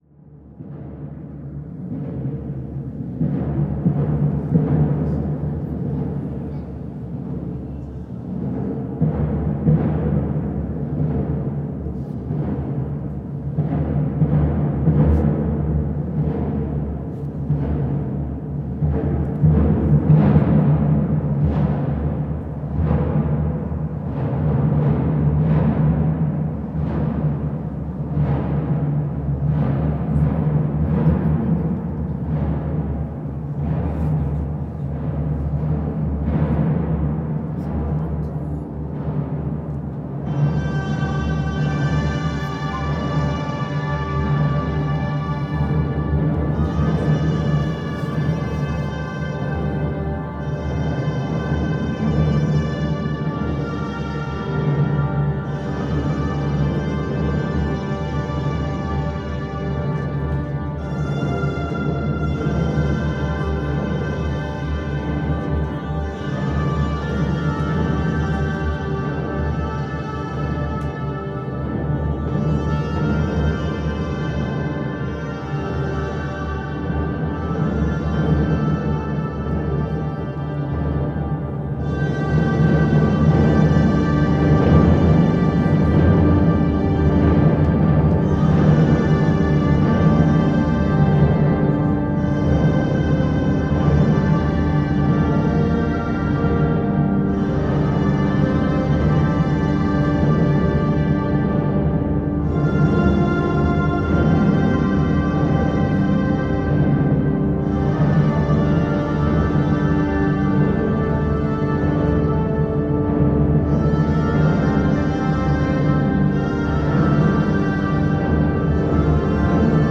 Since 2014, the Song of the Sibyl is represented again in the cathedral of Tarragona. Eritrea, a sibyl daughter of a nymph, answers St. Augustine's questions about the redeemer's second arrival: the Iudicii Signum. This manifestation of religious theater, which was celebrated within many churches and cathedrals, was very popular from the fourteenth century and banned from the guidelines of the Council of Trent.
Cathedral of Tarragona (inside)